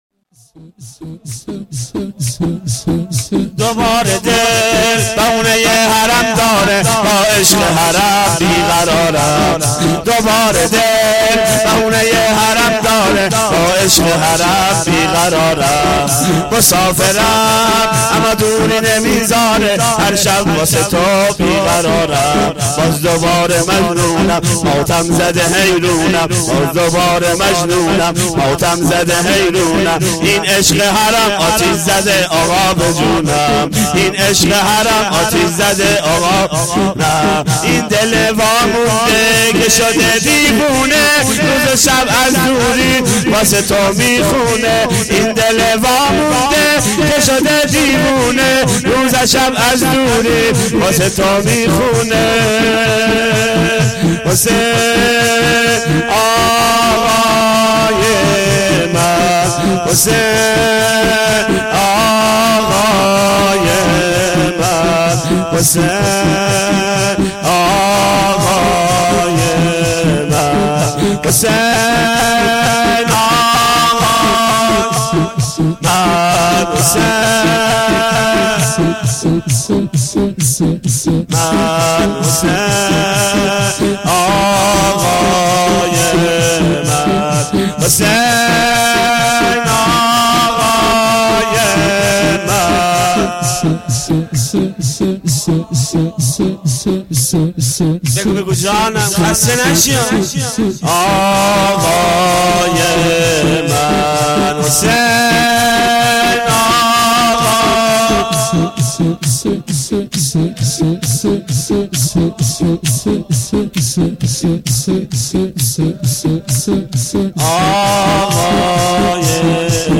شور
روضه